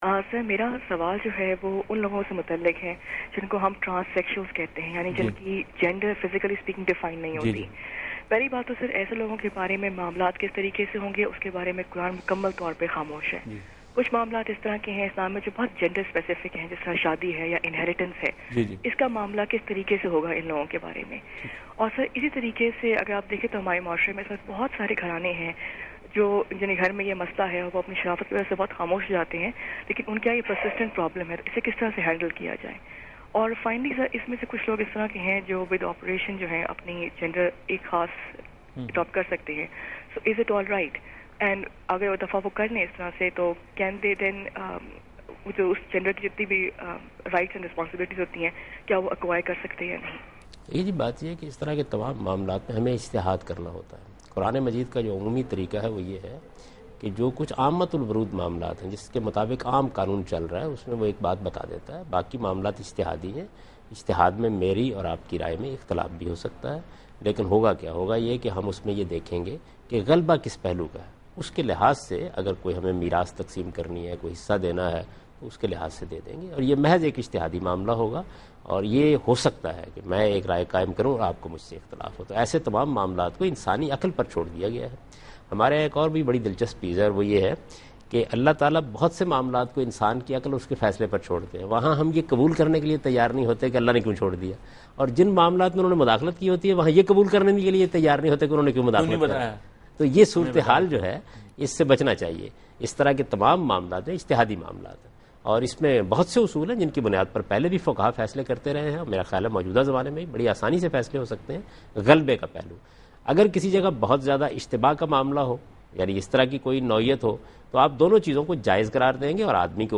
Category: TV Programs / Dunya News / Deen-o-Daanish /
Answer to a Question by Javed Ahmad Ghamidi during a talk show "Deen o Danish" on Dunya News TV